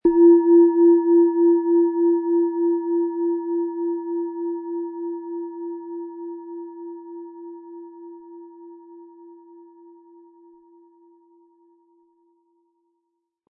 Von Hand getriebene tibetanische Planetenschale Chiron.
Im Sound-Player - Jetzt reinhören können Sie den Original-Ton genau dieser Schale anhören.
Der kräftige Klang und die außergewöhnliche Klangschwingung der traditionellen Herstellung würden uns jedoch fehlen.
Mit Klöppel, den Sie umsonst erhalten, er lässt die Planeten-Klangschale Chiron voll und harmonisch erklingen.
MaterialBronze